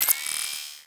Robotic Game Notification 15.wav